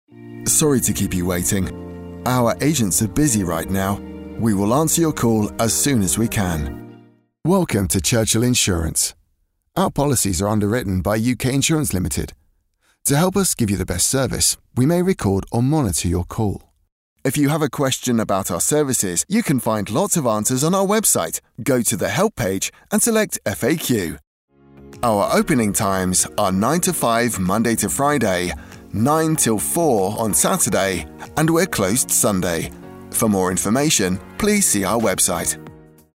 Meu tom amigável e pé no chão, que cai na faixa baixa a média, conecta-se efetivamente com o público de uma maneira coloquial que é envolvente e acessível.
Microfone Neumann TLM193